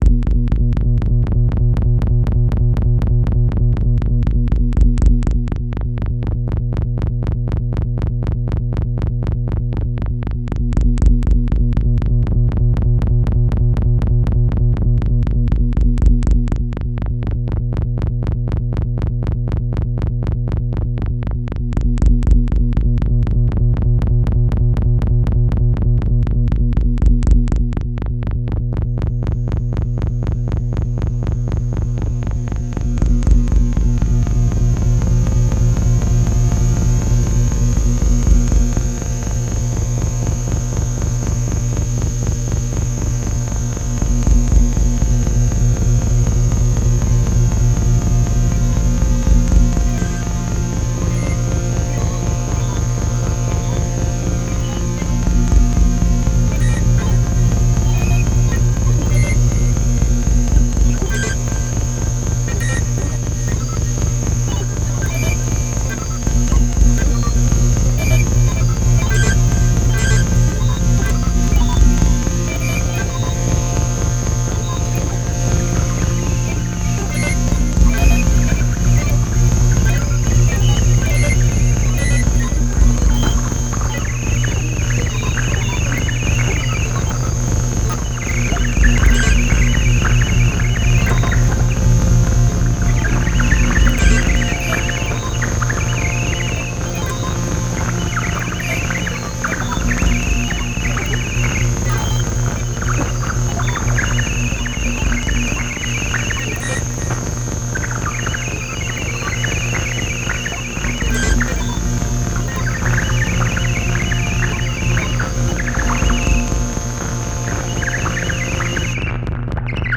Found among my tracks one example of very characteristic A4-ish sounds.
Raw complex pulsations… to accompany the melodic and rhythmical basis on DN4, for example.
(I mixed bass badly here, originally it was much deeper and louder).